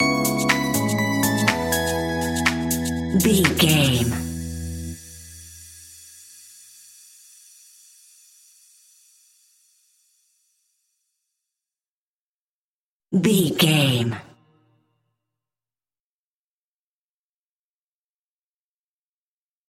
Melodic Stinger.
Ionian/Major
groovy
uplifting
futuristic
energetic
cheerful/happy
repetitive
synthesiser
electric piano
drum machine
electro house
funky house
synth leads
synth bass